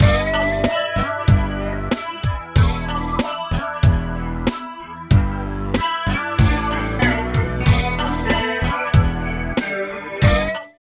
29 LOOP   -R.wav